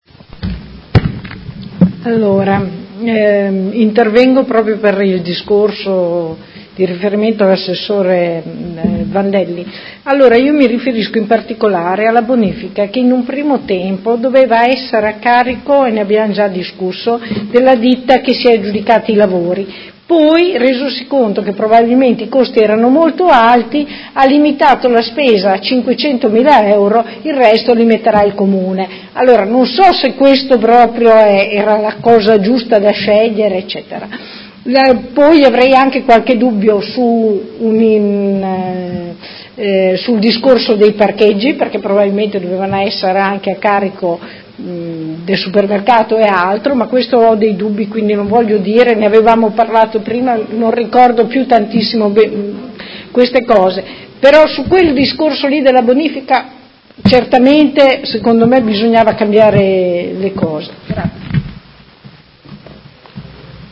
Seduta del 04/04/2019 Dichiarazione di voto.